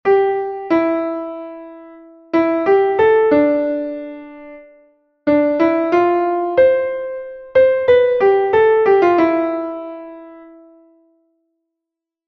andante.mp3